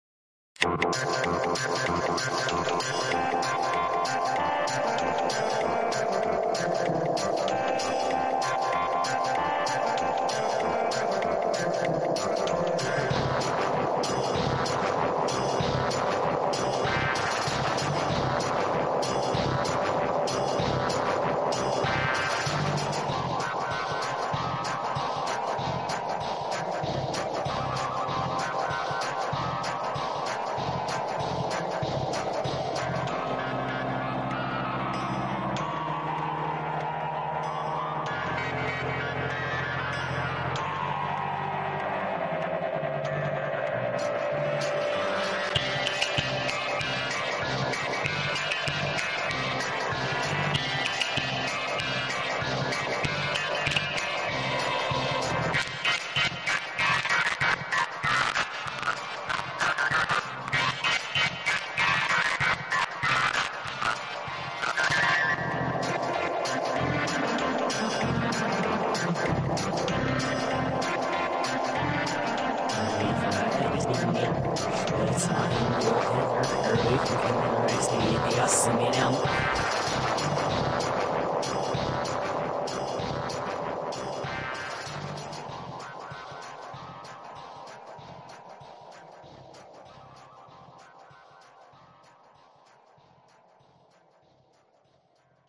Evil_mushroom_kingdom_national_anthem.mp3